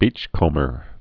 (bēchkōmər)